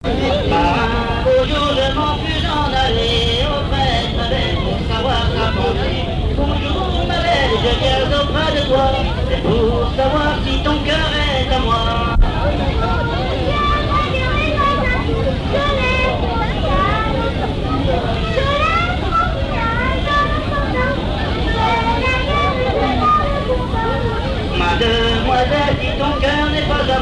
Talmont-Saint-Hilaire
Genre strophique
lors d'une kermesse
Pièce musicale inédite